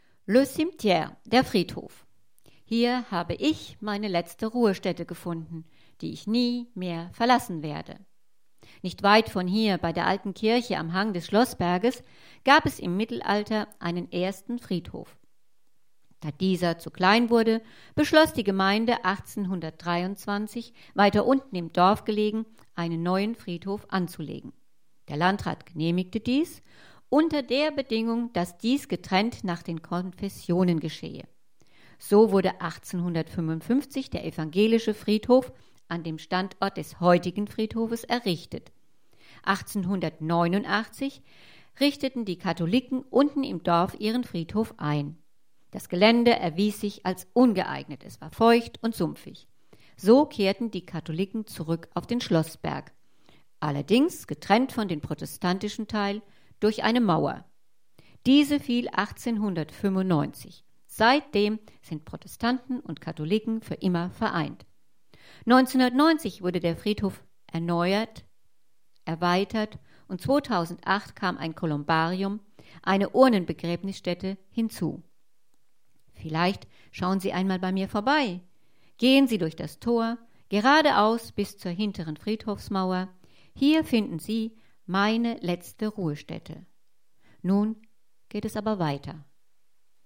Mit ihrem Smartphone brauchen sie nur den QR-Code an jeder Hinweistafel zu scannen und ein Audio-guide wird in deutscher Sprache die Stationen des Spaziergangs erläutern